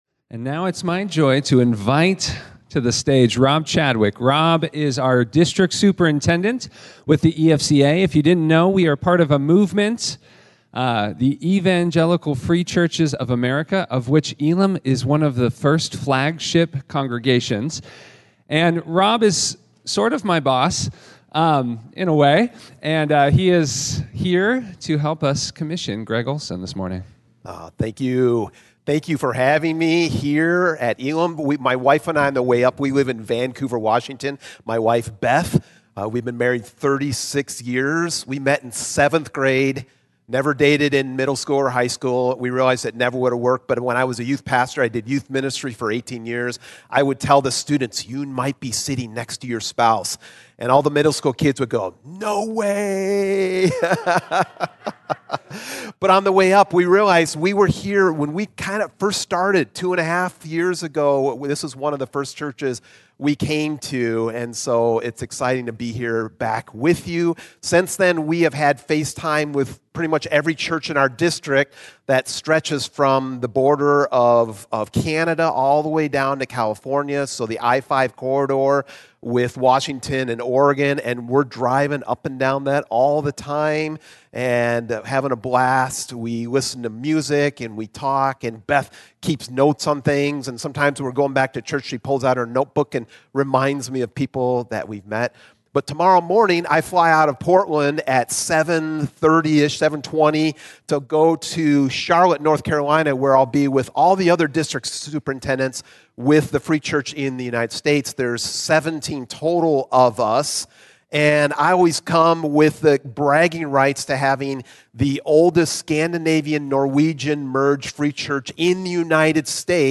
Commissioning service